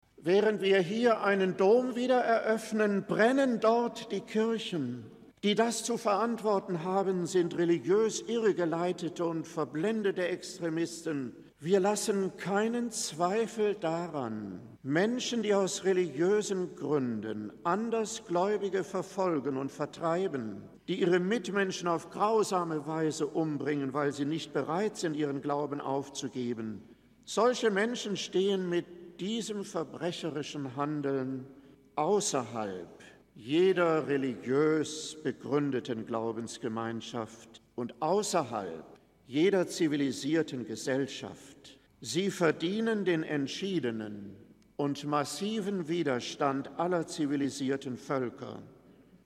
Bischof Norbert Trelle erinnerte in seiner Predigt zur Eröffnung des Hildesheiemr Doms an das Schicksal von Menschen, die aufgrund ihres Glaubens verfolgt werden.